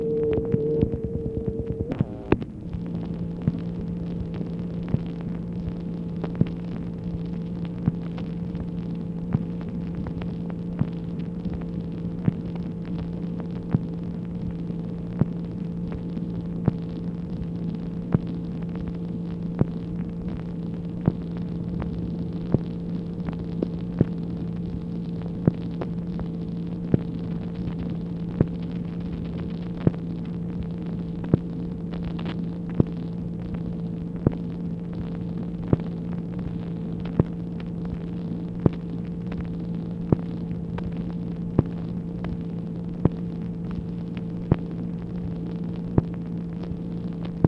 MACHINE NOISE, September 2, 1964
Secret White House Tapes | Lyndon B. Johnson Presidency